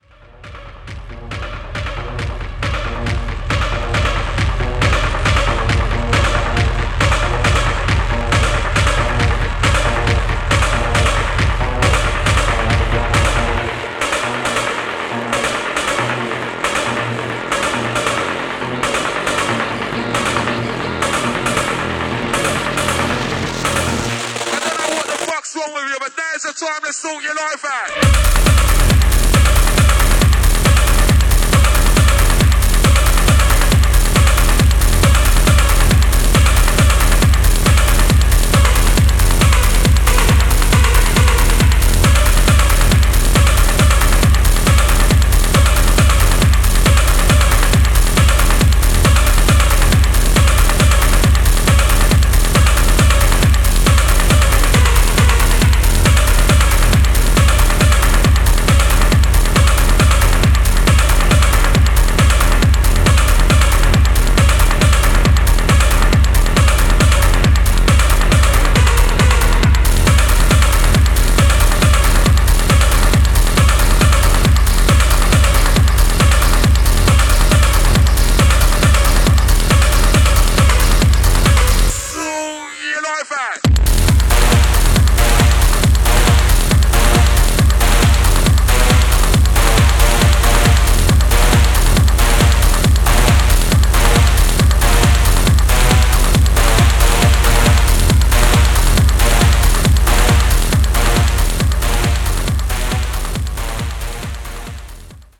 Filed under: Electro / Techno Clip